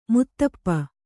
♪ muttappa